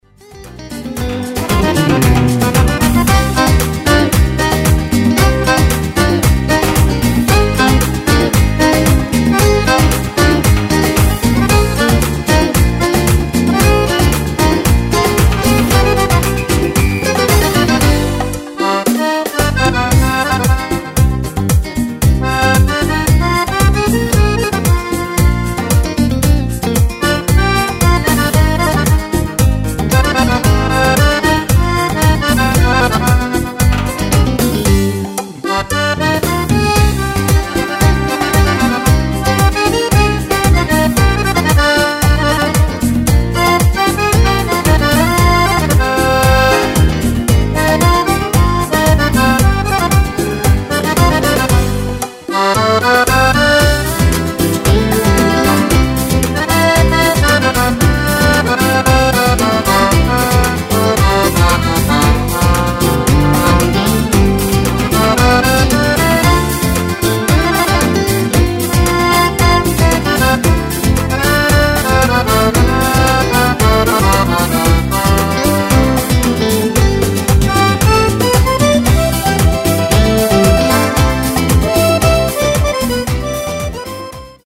Latin gipsy
Fisarmonica